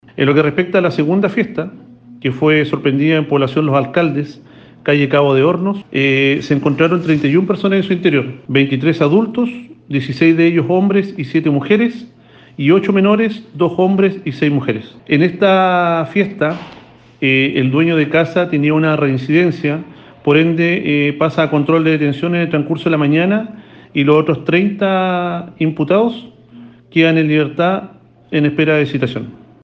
Sin embargo, esta no fue la única actividad ilícita que se controló la noche del sábado en Quellón, porque también en población Los Alcaldes, calle Cabo de Hornos, había en una casa cerca de 30 personas participando de la fiesta, dijo el oficial de Carabineros.